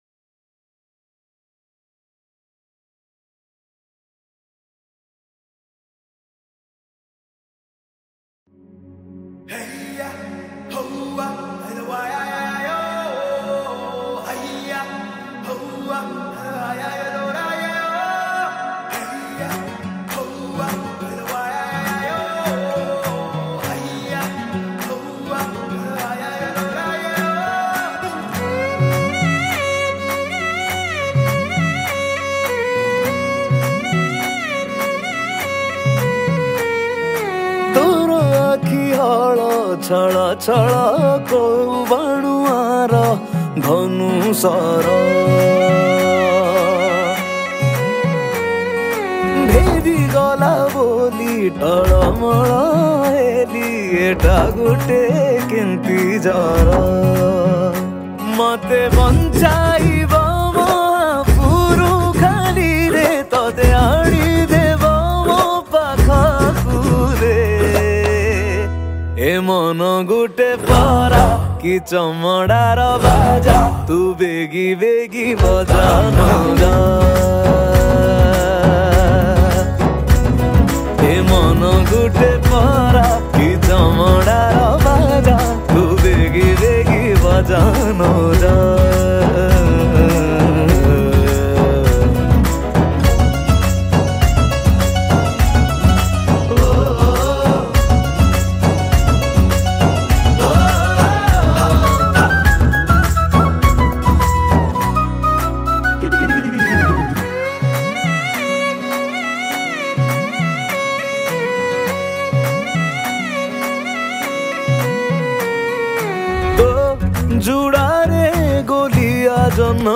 Keyboard and Rhythm
Flute
Guitar
Chorus and Backing Vocals